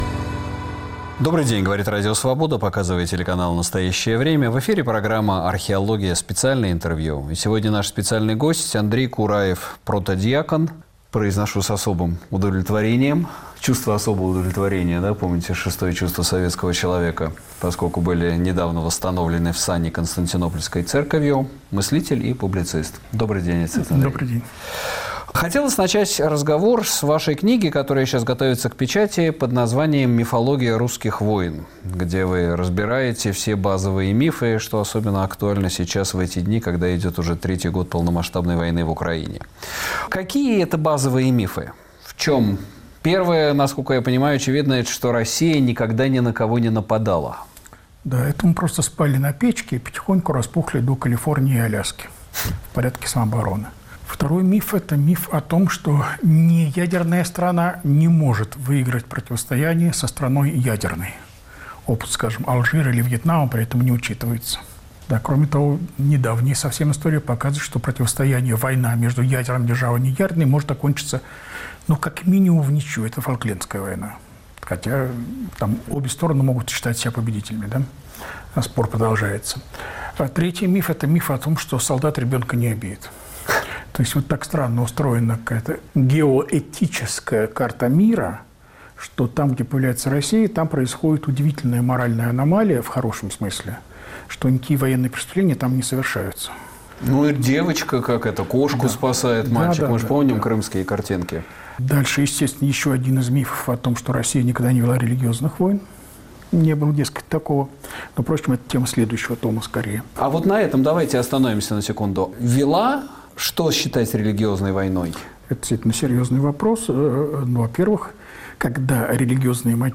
На вопросы Сергея Медведева отвечает протодьякон, мыслитель и публицист Андрей Кураев